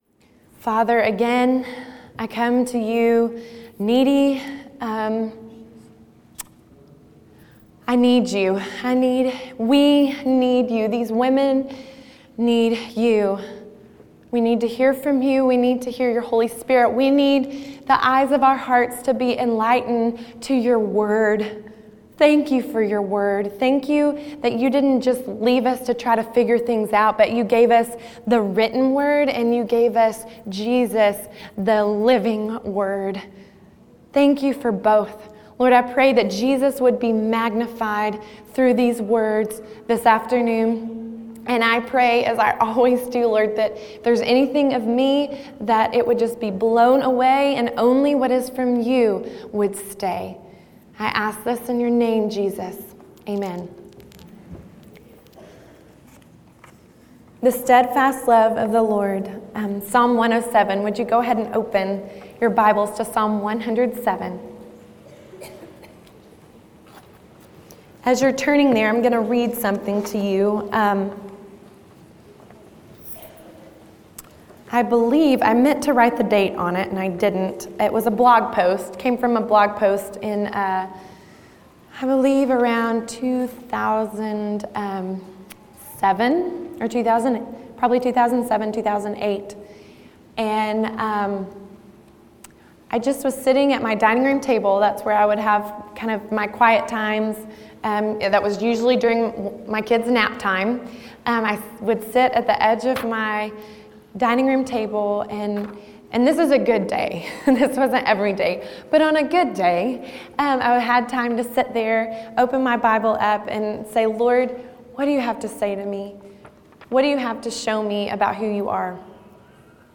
The Steadfast Love of the Lord | True Woman '14 | Events | Revive Our Hearts